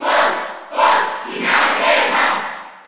Category:Crowd cheers (SSB4) You cannot overwrite this file.
Fox_Cheer_Spanish_PAL_SSB4.ogg